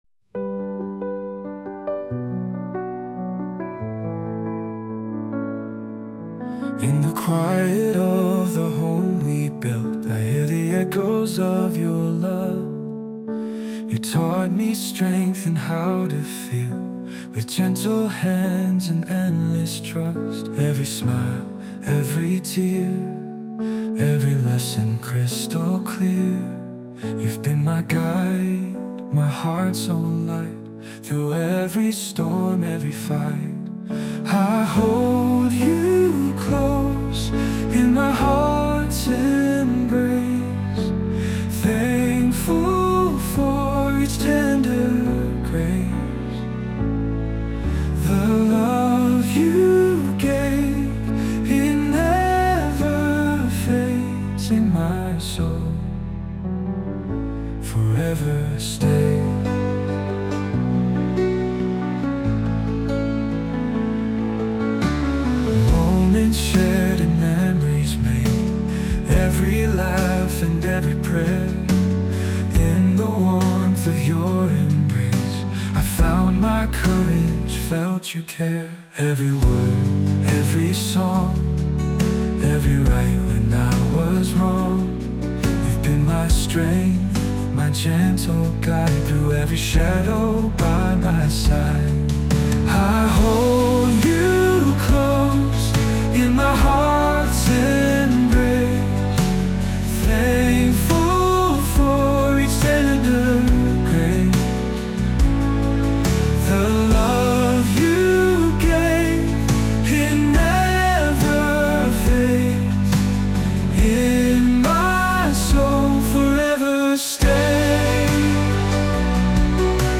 洋楽男性ボーカル著作権フリーBGM ボーカル
男性ボーカル曲（英語）です。